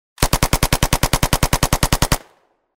Автоматные выстрелы на тренировочном полигоне